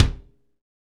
Index of /90_sSampleCDs/Northstar - Drumscapes Roland/KIK_Kicks/KIK_H_H Kicks x
KIK H H K00L.wav